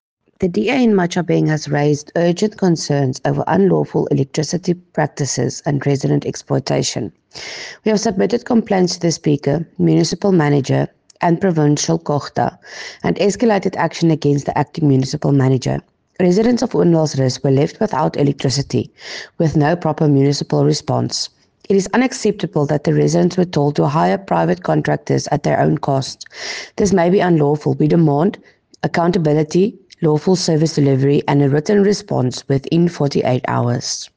Issued by Cllr. Estelle Dansey – DA Councillor Matjhabeng Local Municipality
Afrikaans soundbites by Cllr Estelle Dansey and